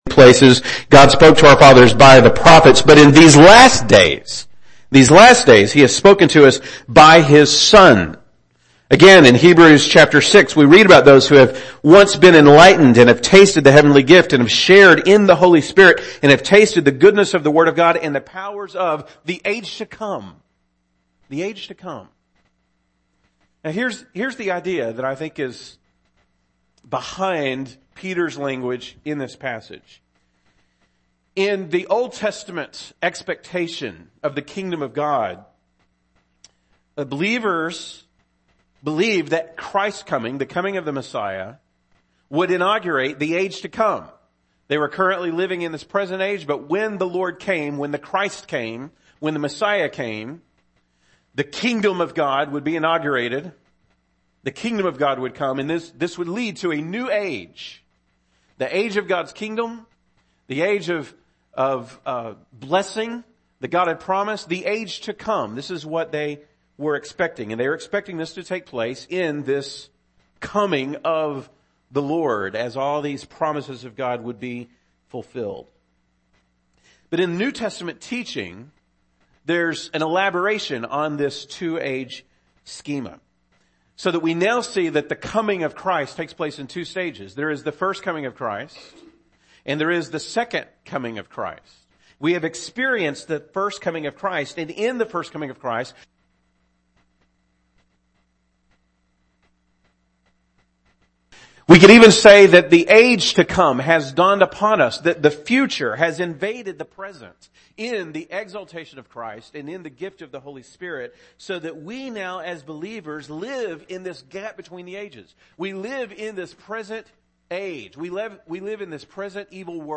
Acts 1-2 play pause mute unmute Download MP3 We apologize that the recording of this sermon was incomplete.